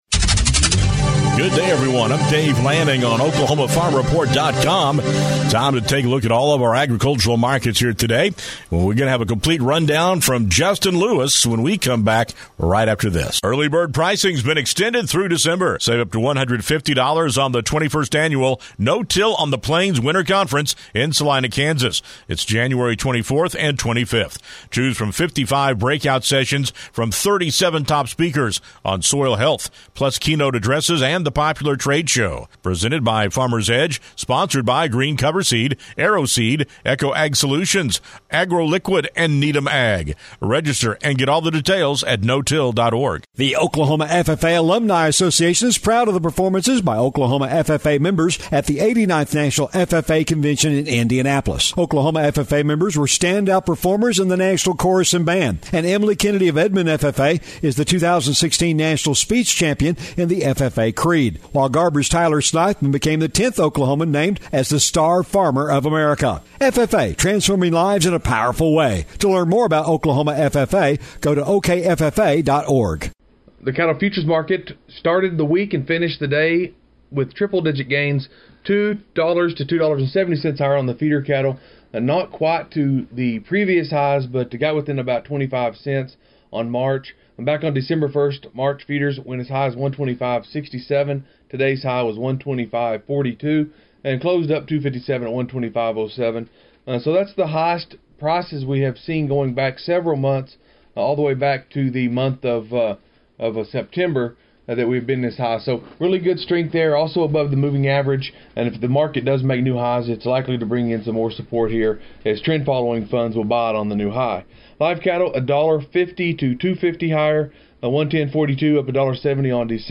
Agricultural News